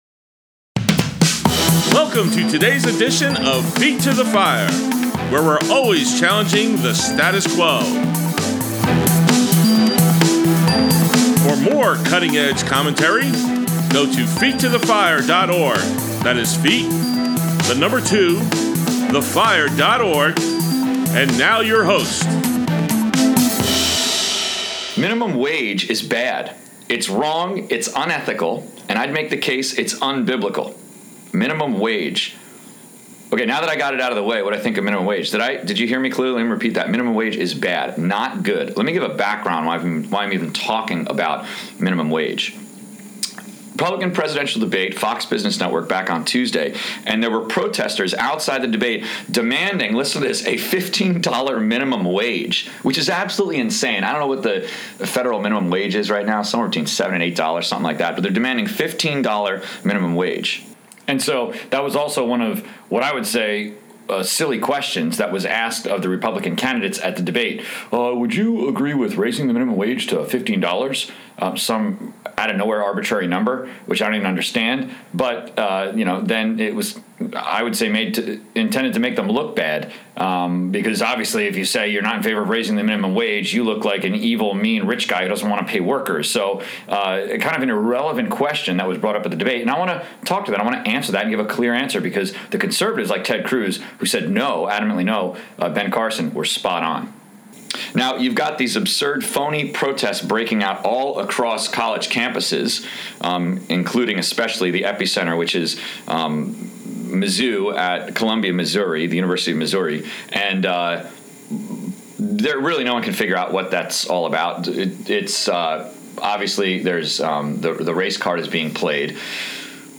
Ep. 43 Minimum Wage Is WRONG: The Unethical Folly of Market Manipulation Is Liberals’ Attempt to Bribe Voters | Feet to the Fire Politics: Conservative Talk Show